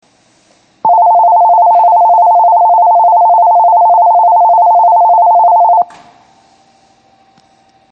仙石線ホームは地下にあり、通常のROMベルを使用しています。
９番線発車ベル